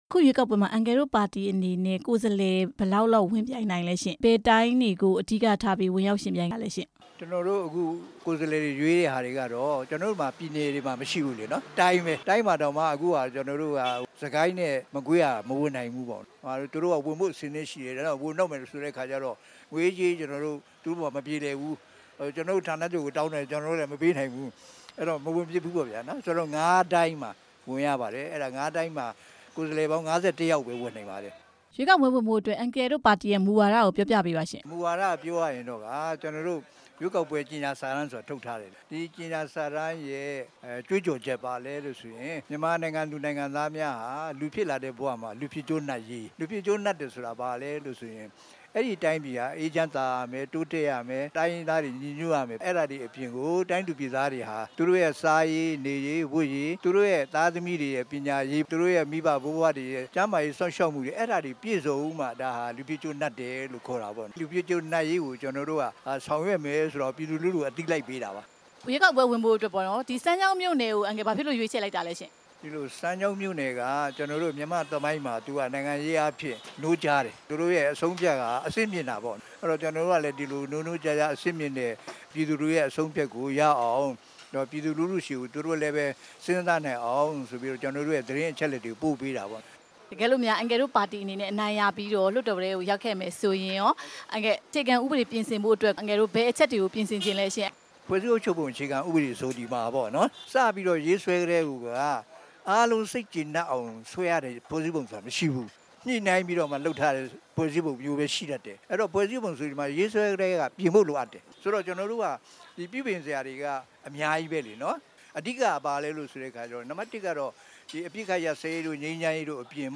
ရန်ကုန်မြို့ စမ်းချောင်းမြို့နယ်မှာ မနေ့က ရွေးကောက်ပွဲအတွက် မဲဆွယ်စည်းရုံးစဉ် ဒီမိုကရက်တစ်ပါတီ (မြန်မာ) ဥက္ကဌ ဦးသုဝေက ပါတီရဲ့မူဝါဒနဲ့ ပတ်သက်ပြီး ပြောခဲ့တာဖြစ်ပါတယ်။